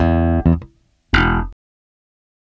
Bass Lick 34-09.wav